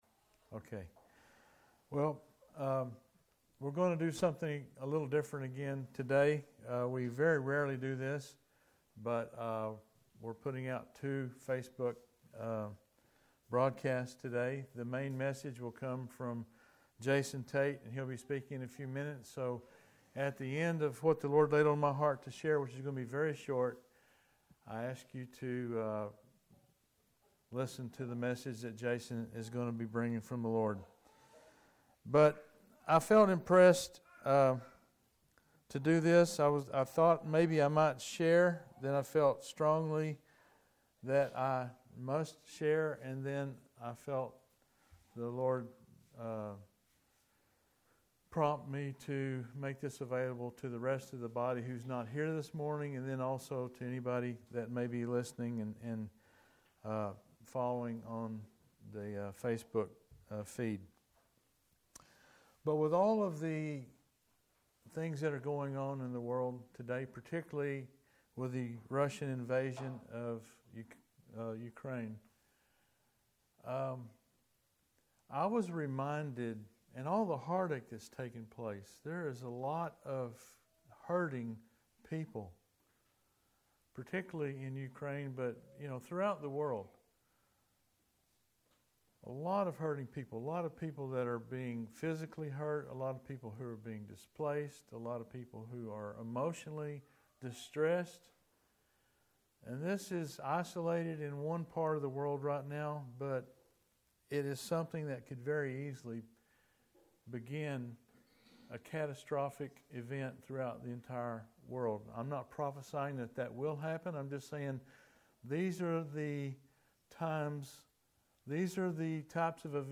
End Times Message